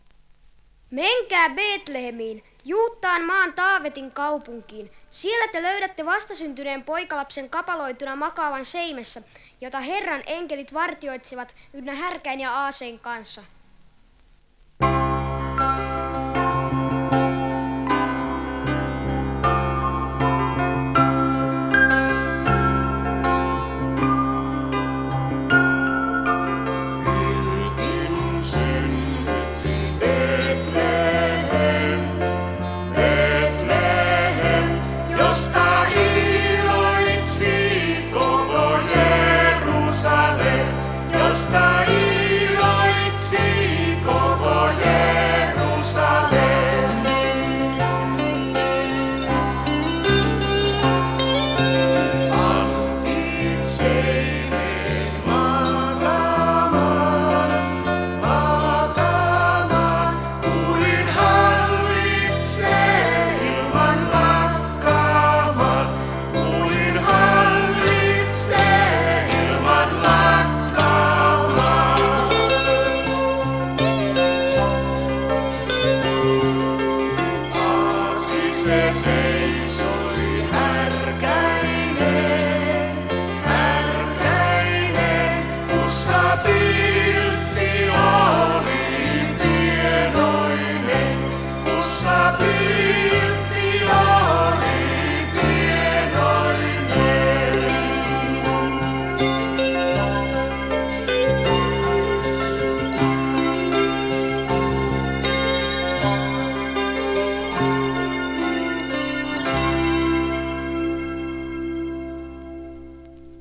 Hän myös soitti kosketinsoittimet.